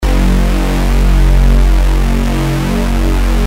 Synth bass sound
bass_original.mp3